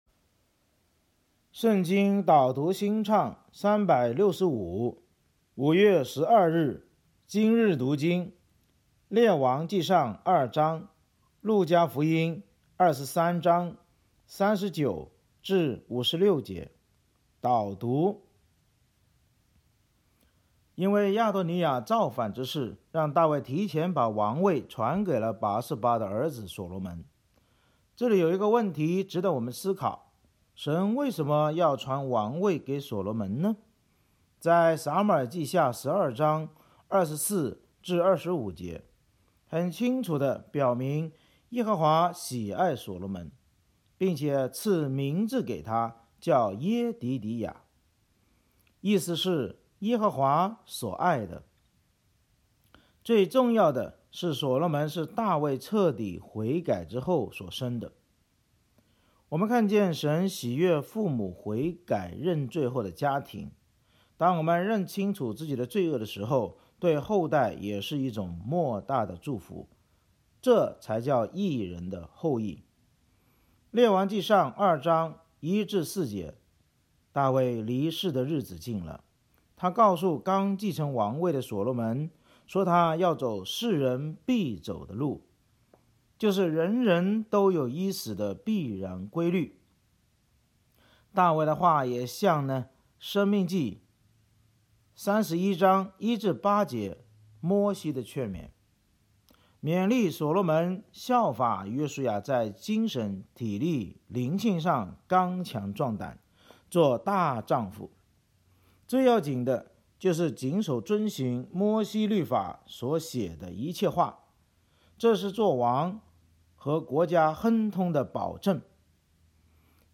【经文朗读】